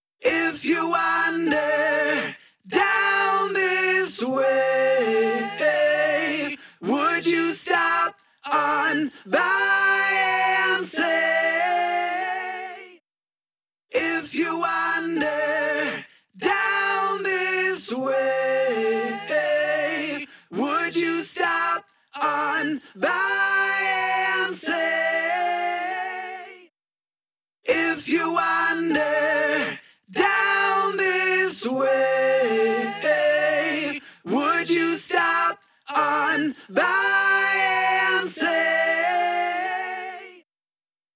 くすんだ録音へのトーナルな高域の追加や、ミックスの中で明るく輝かせるためのボーカルへの空気感の追加を容易に実現
Sheen-Machine-Enhance.wav